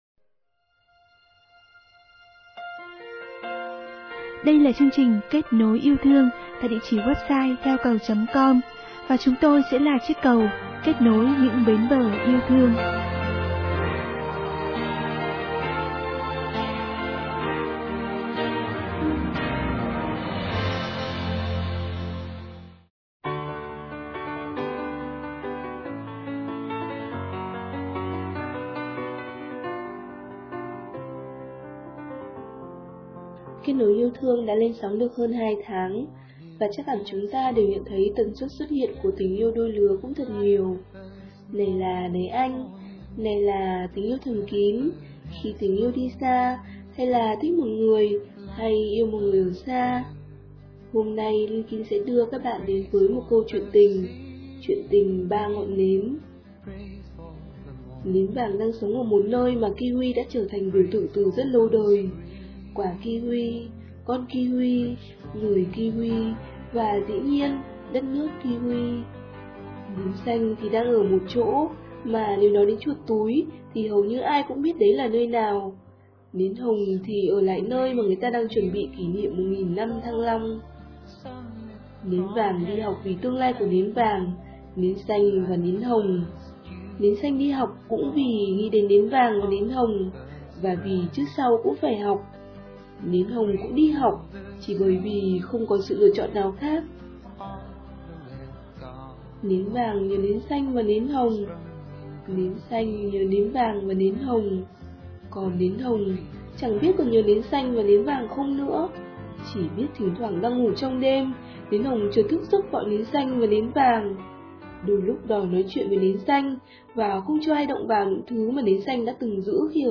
Thích nhất là đoạn madolin đầu bài hat..
giọng nghẹn ngào cảm cúm 😀
2010 at 6:53 sáng Chả nghe rõ hết, chả hiểu hết MC đọc gì cả….hix Nhưng vẫn thích bài hát…